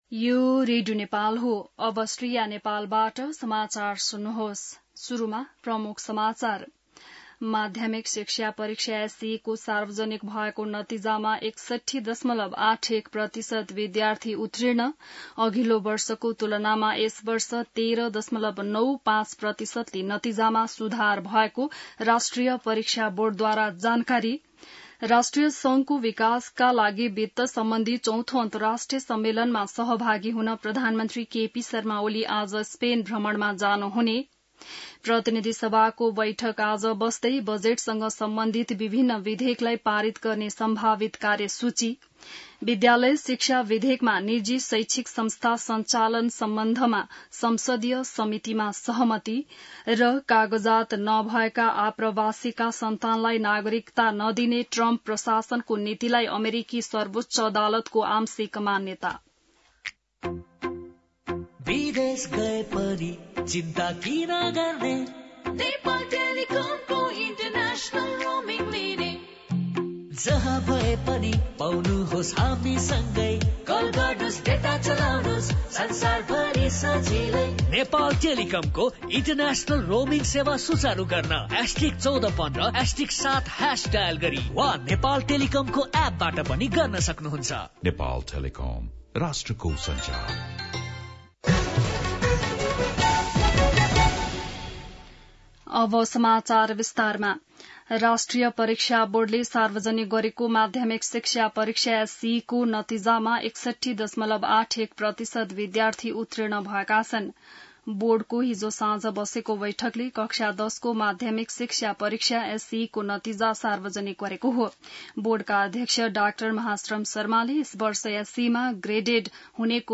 An online outlet of Nepal's national radio broadcaster
बिहान ७ बजेको नेपाली समाचार : १४ असार , २०८२